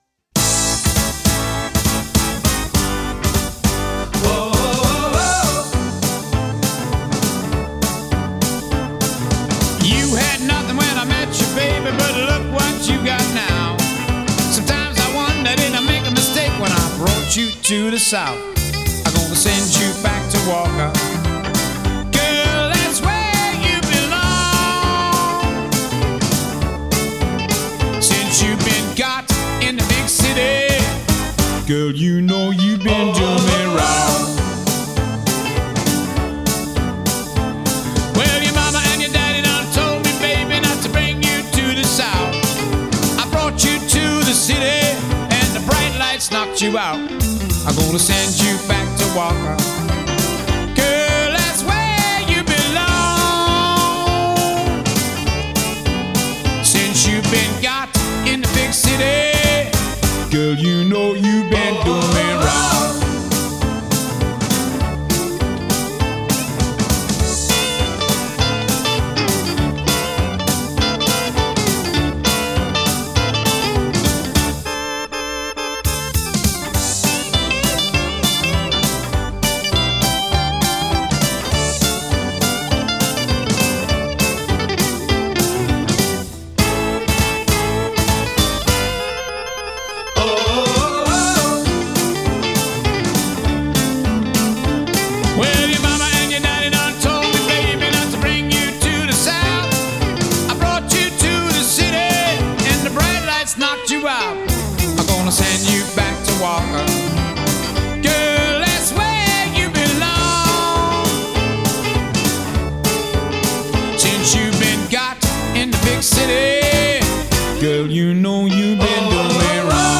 Жанр: психоделический рок, блюз-рок, Ритм-н-блюз, Рок
Genre: Blues, Rock